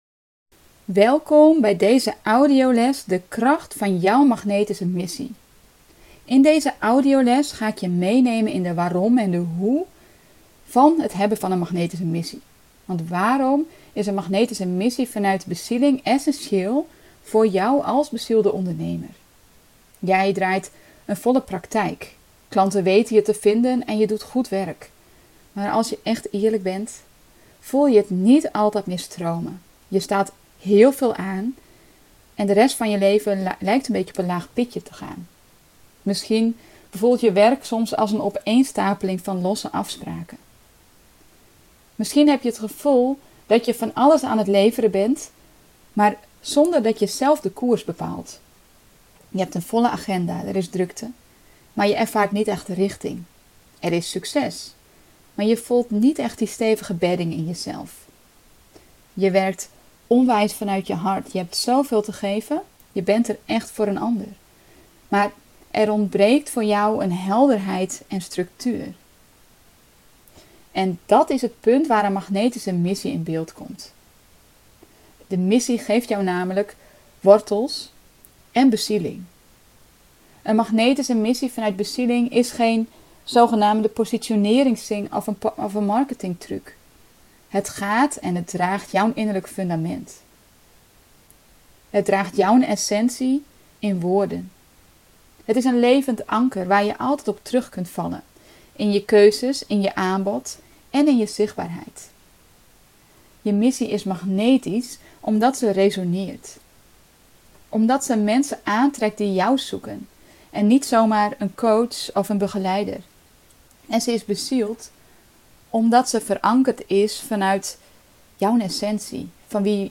AUDIOLES De kracht van een magnetische missie